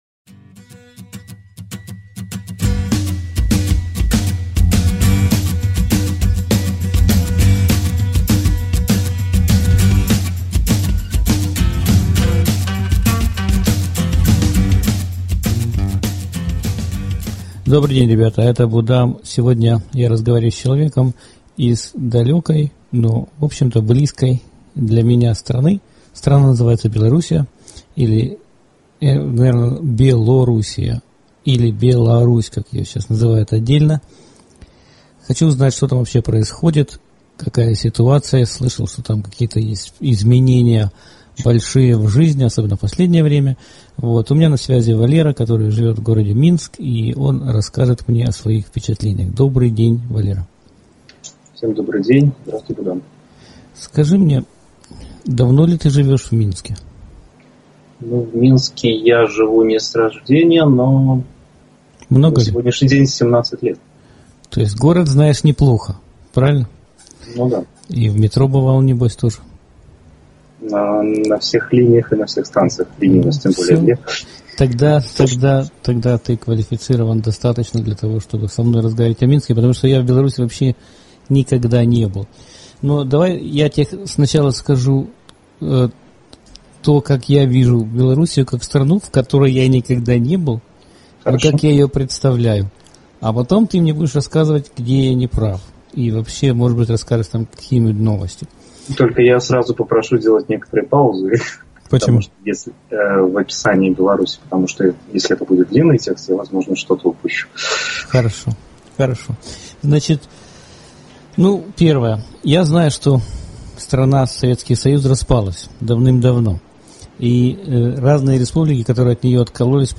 2011 Павольнa размова з мінчанінам.